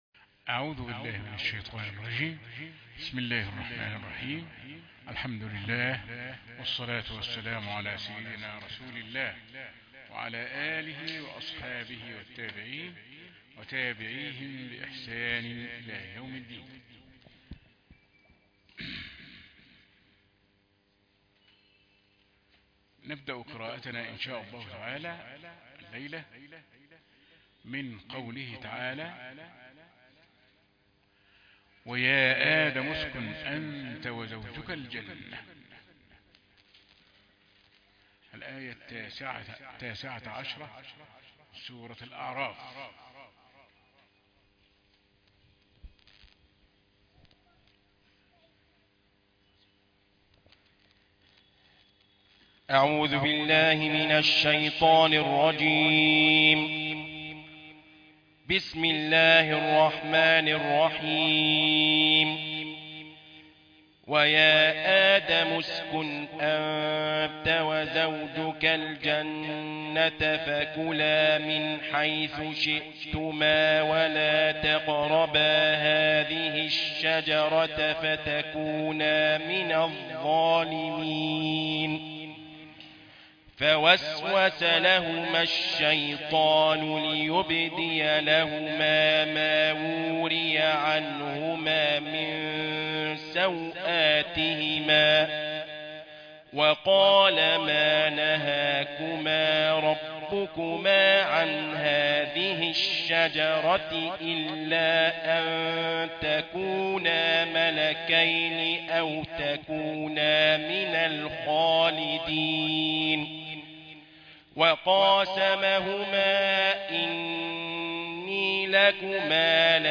سورة الأعراف الآية 19 - مقرأة 8 ربيع آخر 1441 هـ